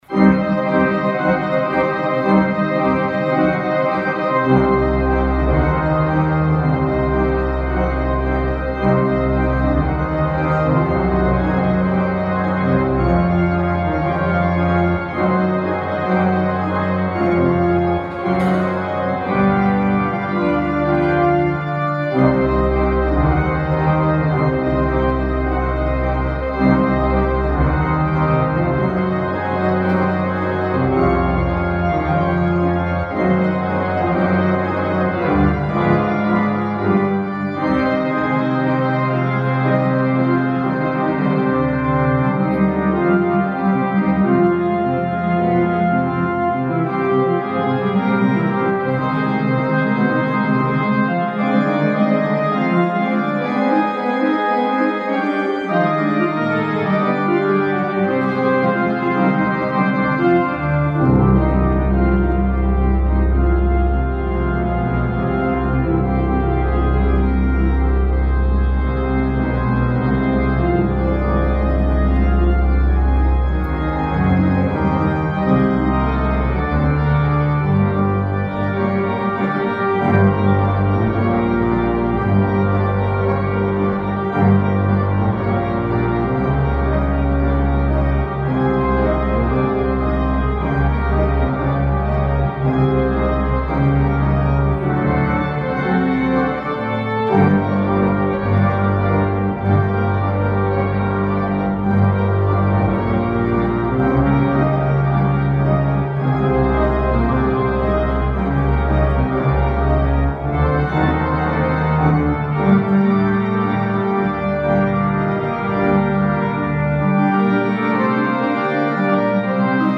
👆클릭하시면 성령강림대축일 미사 오르간 연주곡(퇴장곡)을 들으실 수 있습니다.